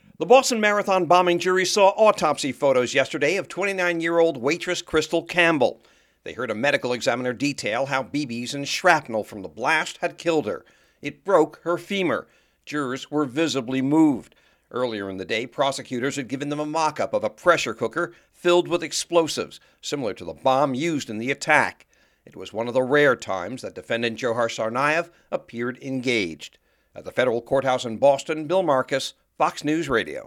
FROM FEDERAL COURTHOUSE IN BOSTON.